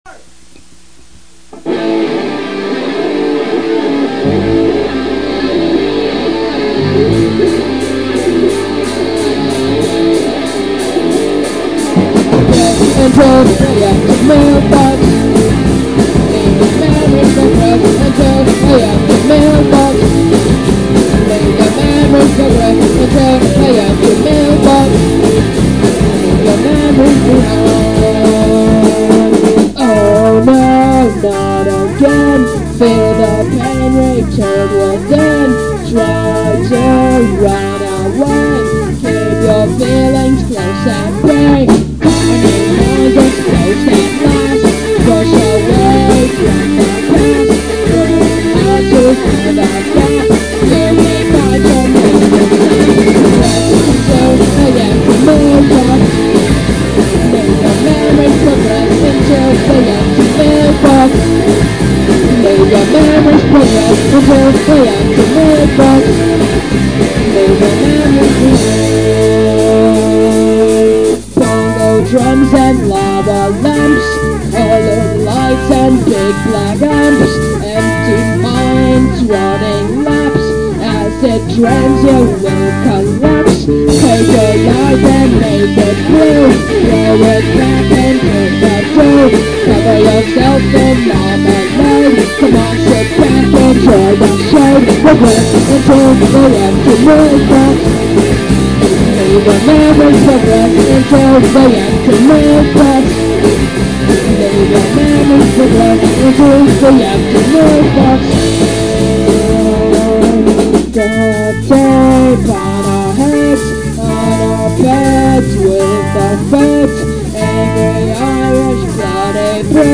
Coolness of melodyness